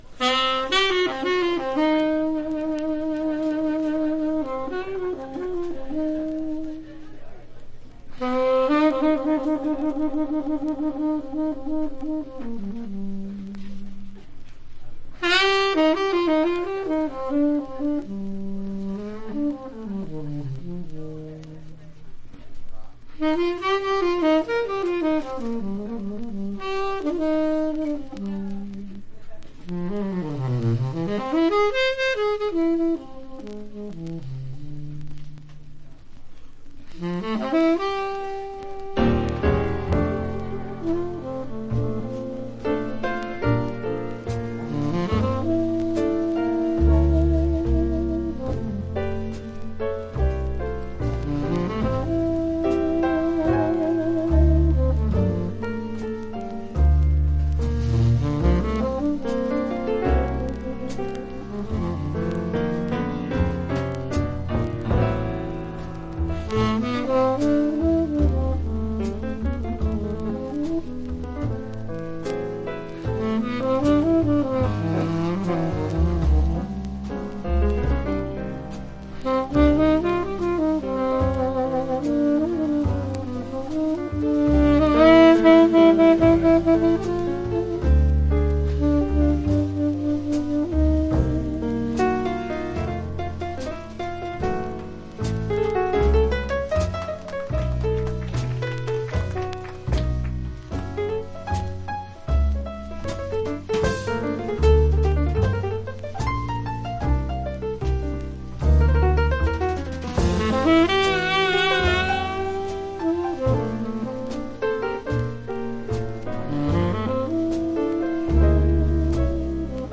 ワンホーンものLive好内容盤